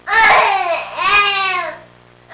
Baby Crying Sound Effect
Download a high-quality baby crying sound effect.
baby-crying-2.mp3